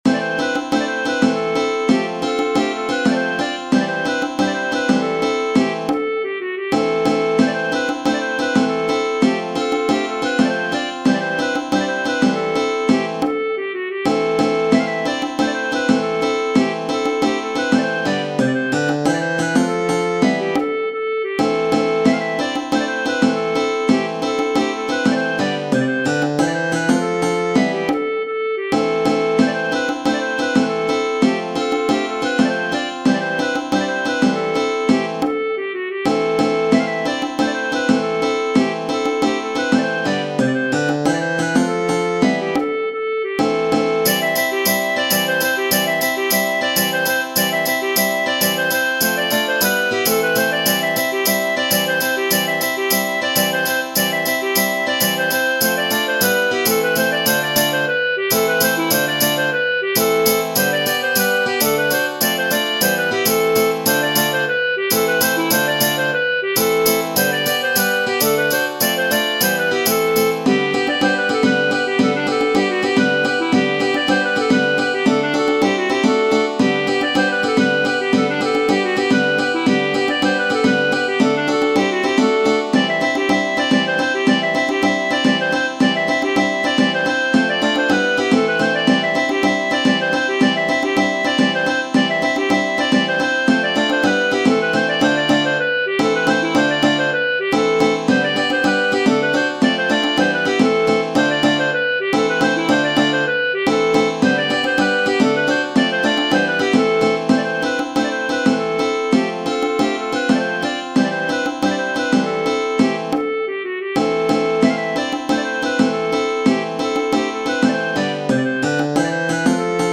Tradizionale Genere: Folk "Petrovo Horo" è un brano musicale e un ballo che fanno parte entrambi del repertorio folk della Bulgaria.